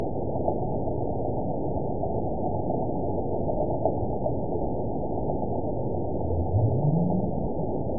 event 911334 date 02/22/22 time 12:48:37 GMT (3 years, 3 months ago) score 9.58 location TSS-AB02 detected by nrw target species NRW annotations +NRW Spectrogram: Frequency (kHz) vs. Time (s) audio not available .wav